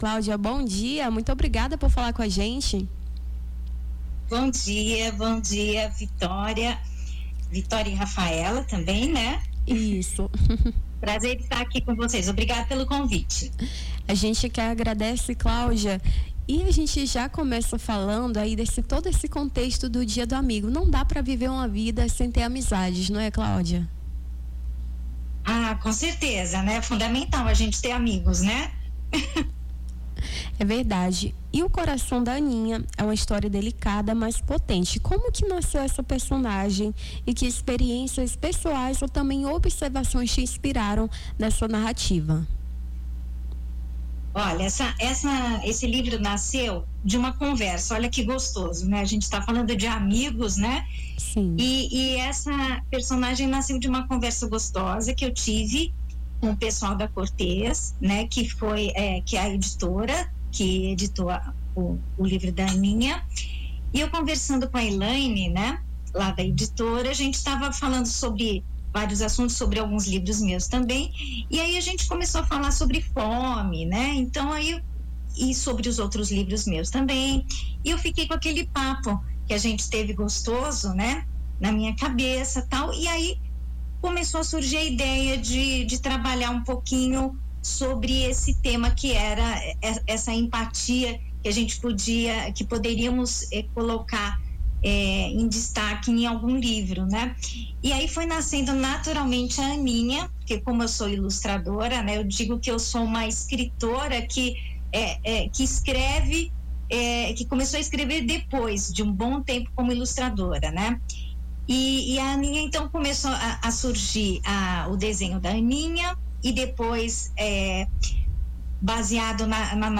Nome do Artista - CENSURA - ENTREVISTA (DIA DO AMIGO) 21-07-25.mp3